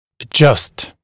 [ə’ʤʌst][э’джаст]регулировать, настраивать